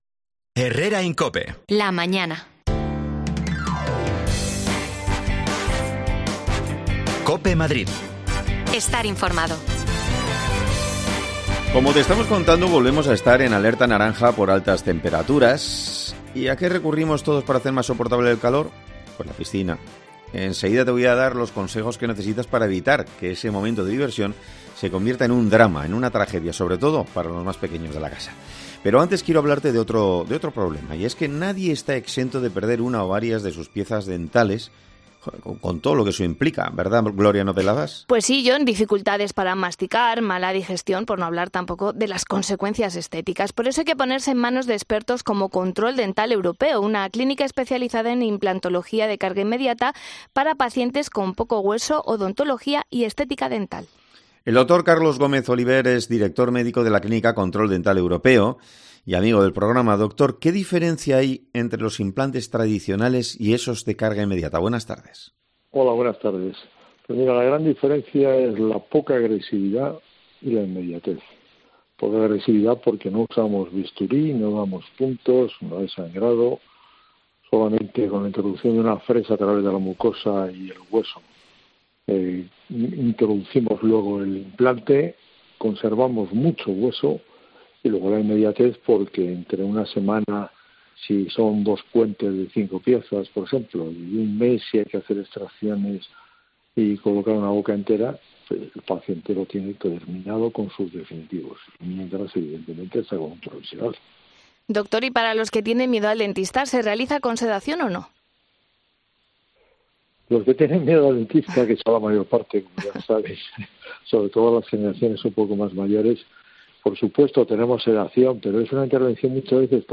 Los peligros de ahogamientos en piscinas privadas y a las horas de mediodía son la principal causa de muerte en menores. Hablamos con un coordinador del SEMES en socorrismo